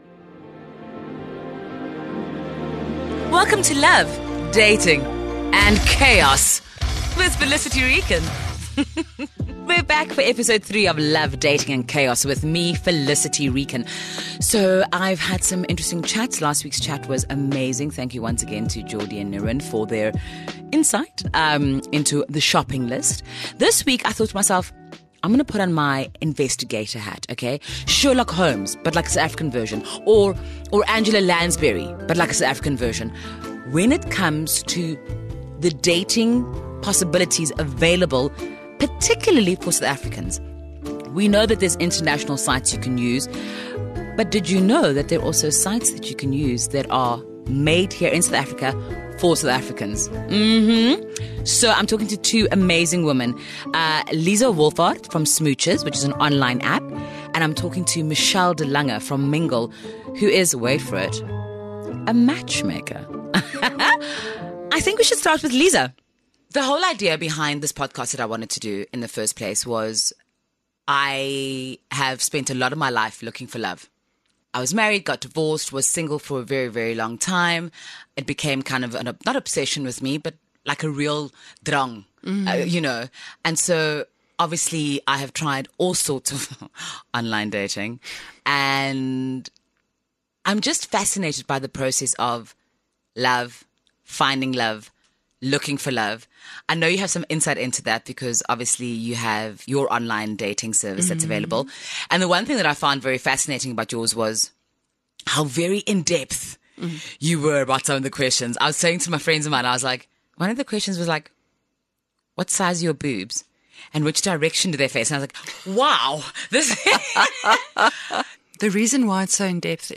I’m joined by two incredible women who have made a career out of hitching people up.